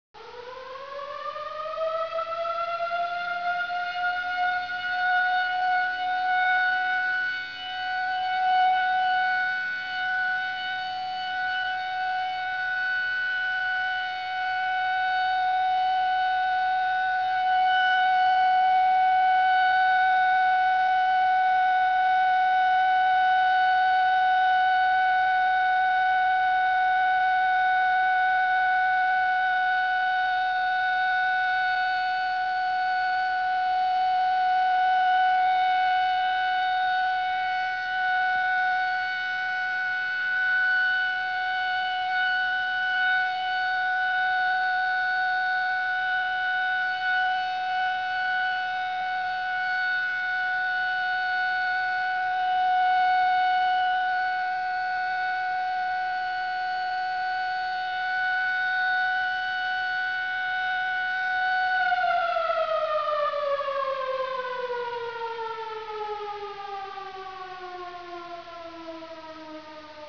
Sirenensignale
• Alarm: 1 Minute auf- und abschwellender Heulton – Gefahr!
sirene-alarm.wav